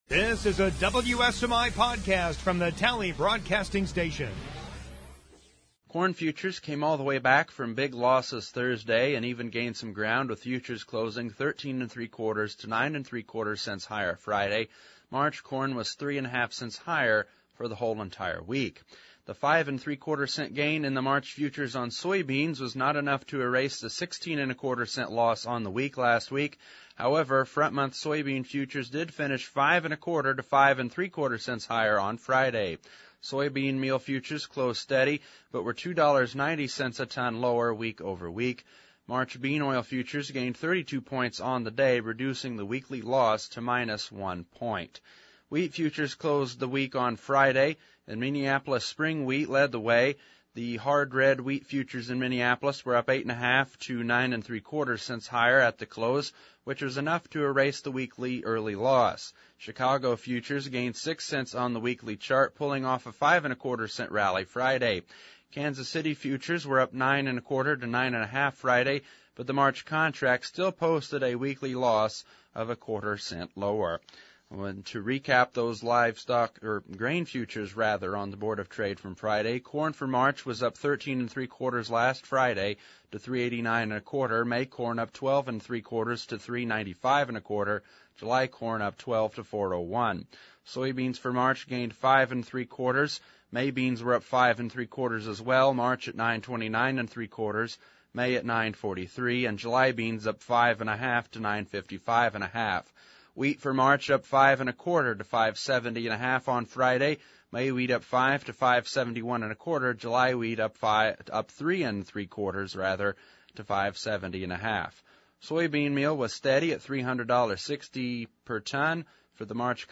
Interview w